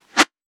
weapon_bullet_flyby_03.wav